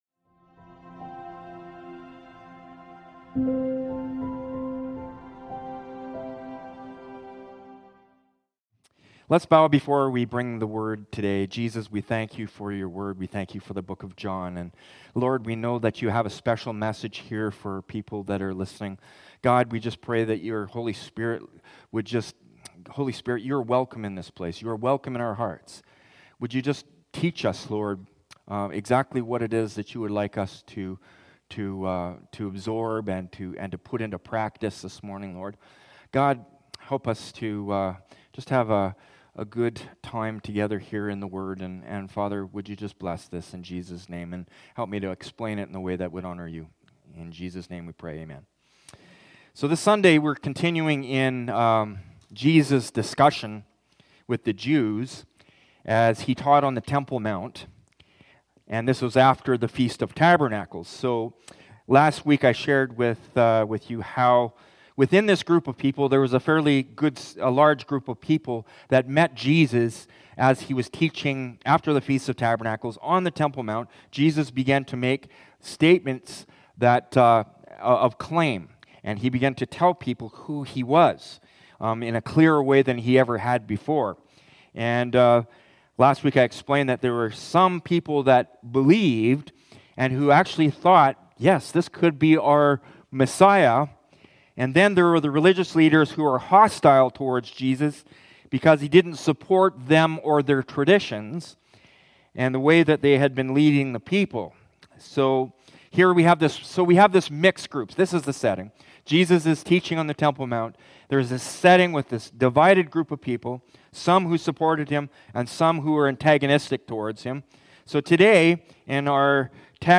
Sermons | Hillside Community Church